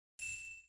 notifications-bell.mp3